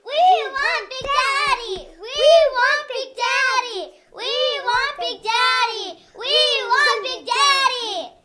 描述：记录两个孩子在热铁皮屋顶上生产猫
Tag: 菜市场买菜 好同事 孩子 孩子